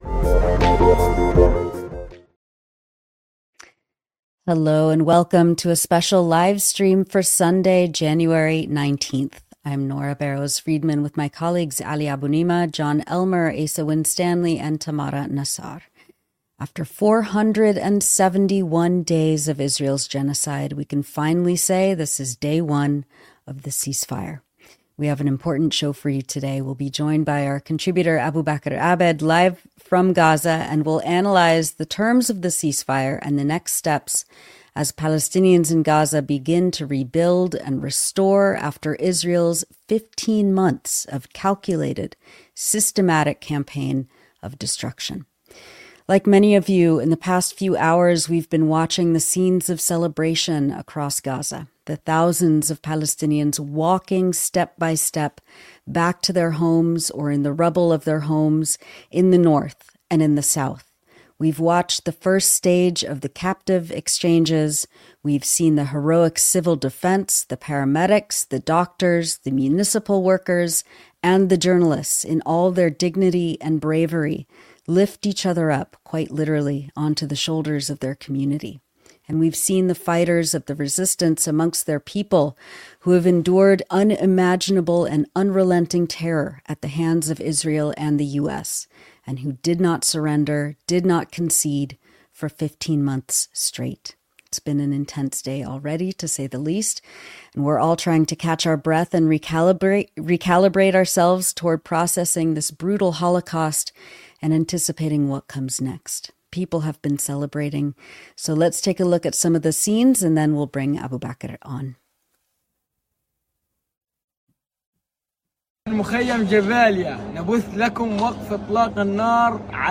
In an impromptu livestream on Sunday afternoon, editors discussed the beginning of the ceasefire and provided analysis as the first exchange of prisoners between Israel and Hamas started.